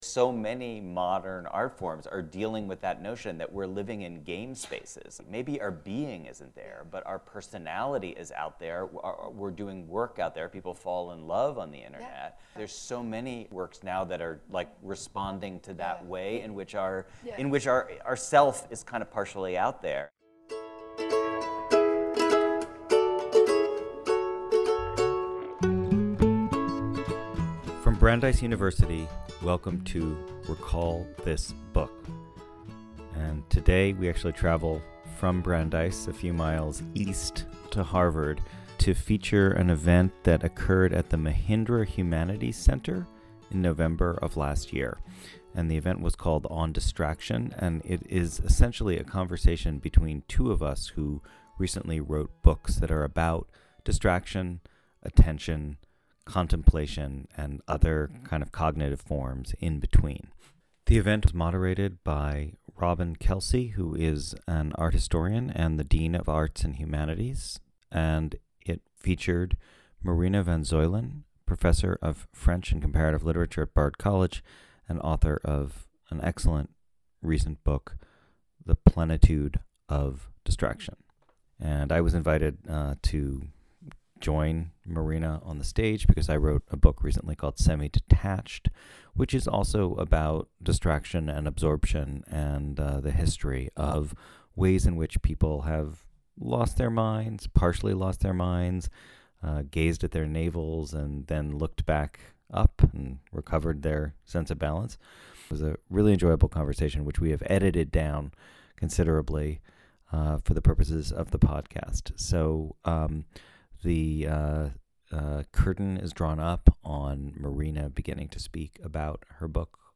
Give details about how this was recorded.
Our podcast presentation of it has been edited and condensed for clarity; a video of the full event is available on their channel .